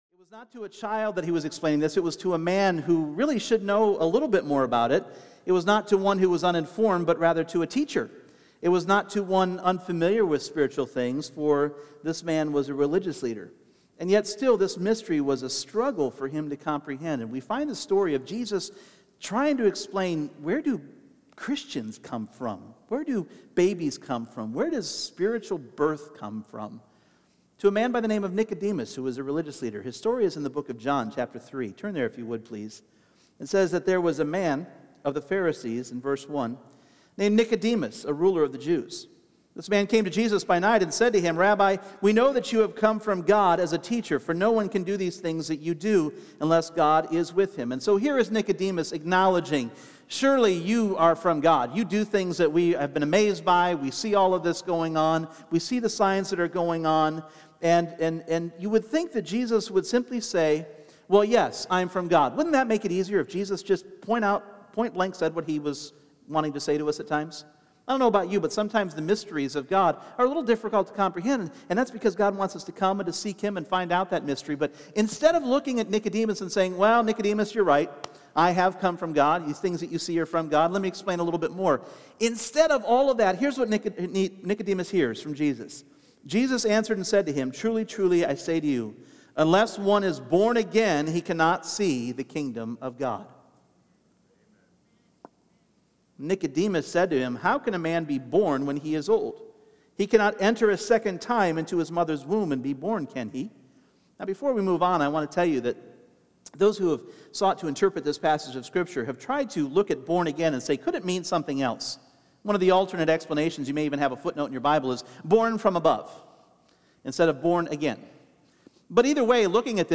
Individual Messages Service Type: Sunday Morning What does it mean to be "Born Again?"